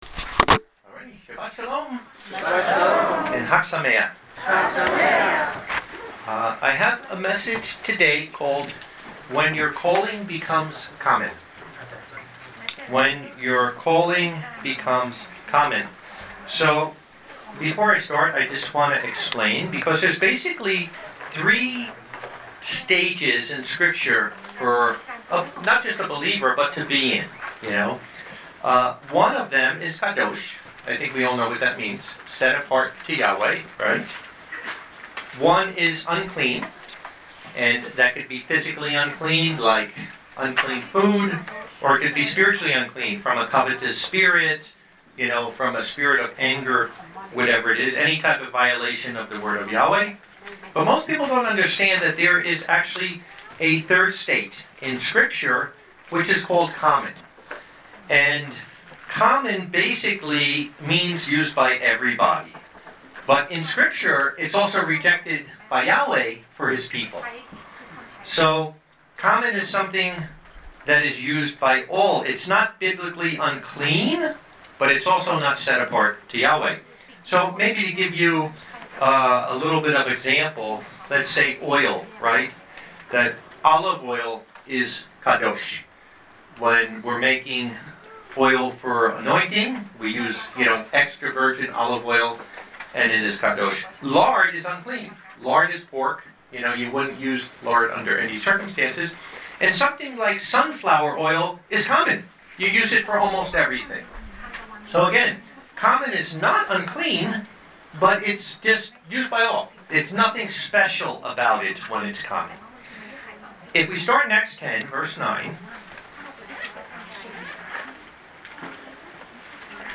Sermons When Your Calling Becomes Common